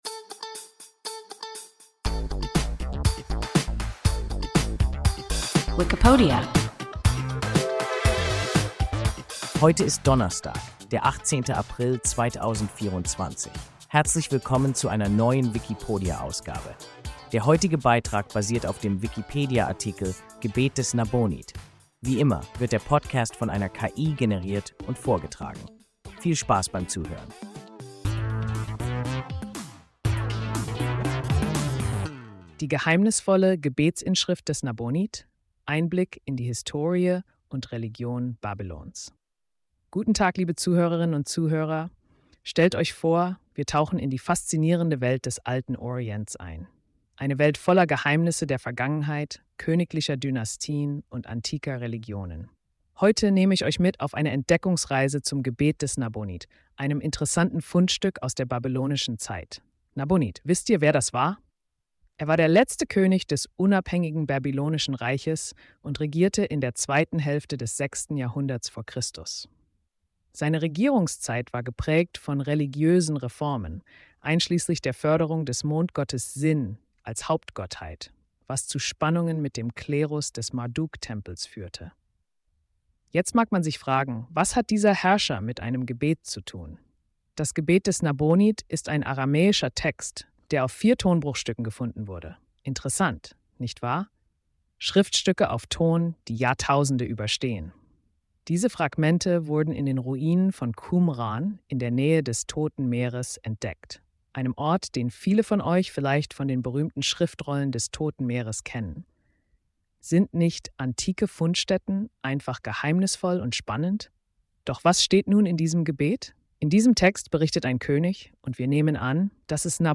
Gebet des Nabonid – WIKIPODIA – ein KI Podcast